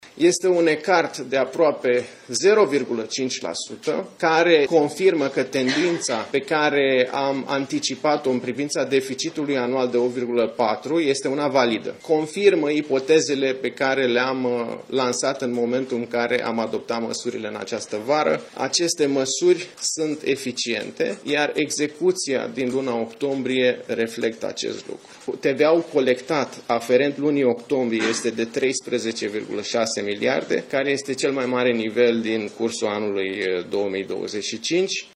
Ministrul Finanțelor, Alexandru Nazare, într-o conferință de presă la Palatul Victoria: „TVA colectată în luna octombrie este de 13, 6 miliarde, care este cel mai mare din cursul anului 2025”